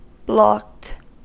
Ääntäminen
Vaihtoehtoiset kirjoitusmuodot (vanhentunut) blockt Ääntäminen US Haettu sana löytyi näillä lähdekielillä: englanti Blocked on sanan block partisiipin perfekti.